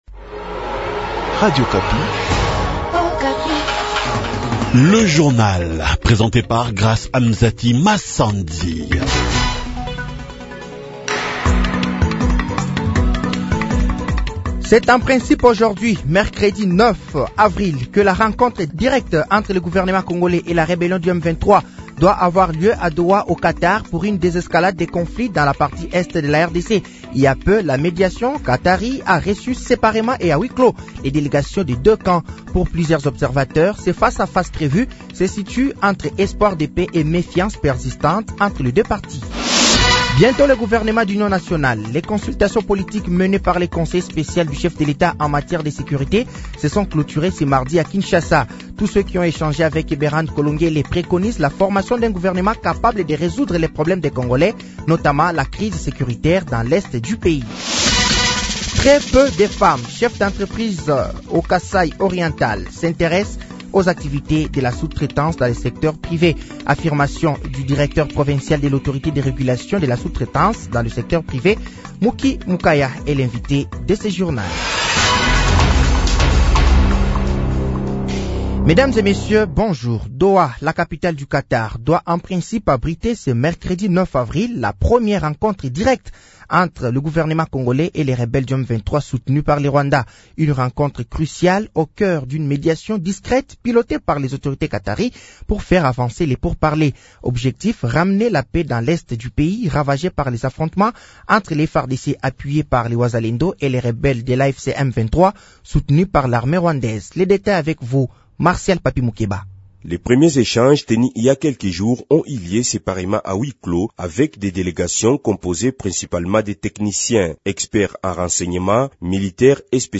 Journal français de 6h de ce mercredi 09 avril 2025